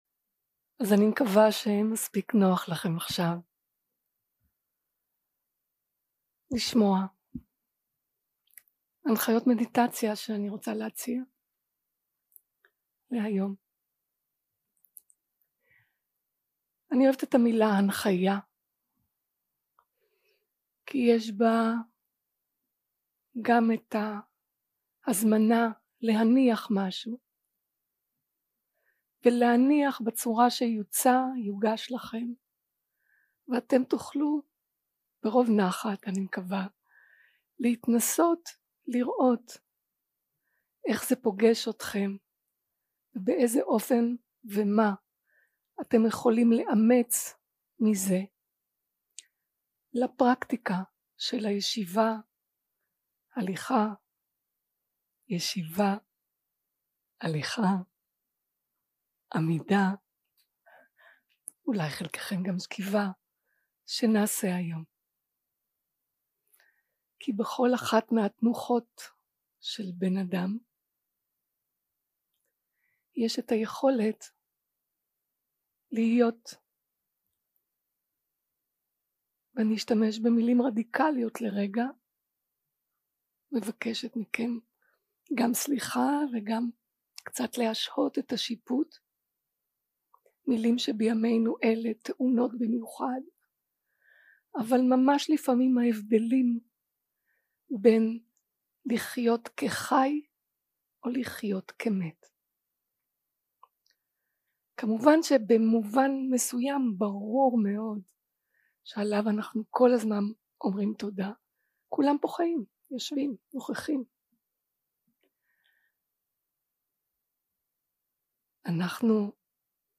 Guided meditation שפת ההקלטה